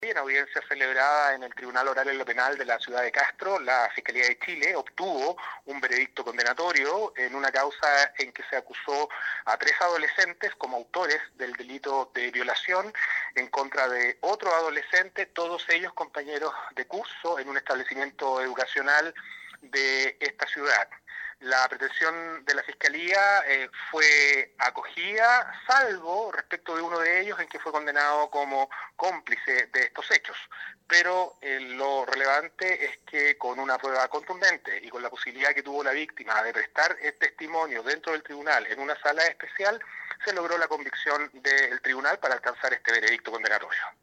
El Fiscal Enrique Canales se refirió a lo determinado por el tribunal oral de Castro, por violación y abuso en la capital provincial.
11-FISCAL-ENRIQUE-CANALES.mp3